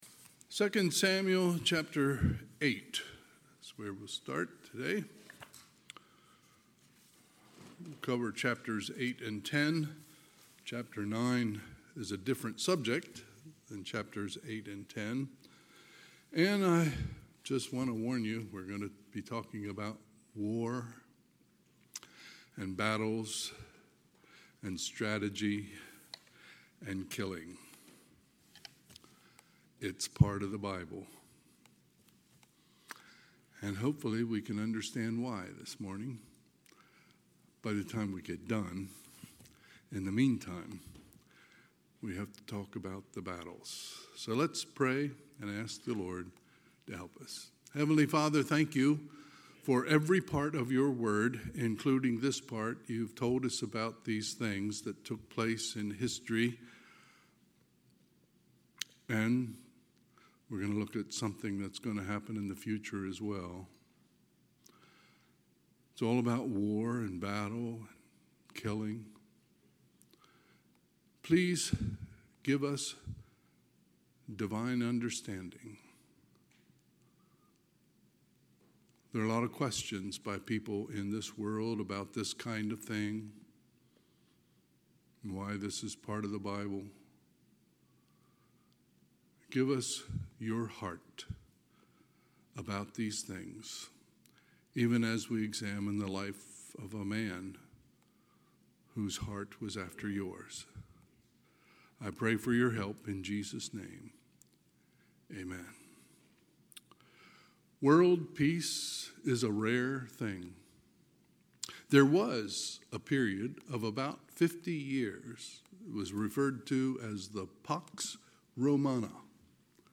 Sunday, July 27, 2025 – Sunday AM